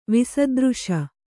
♪ visadřśa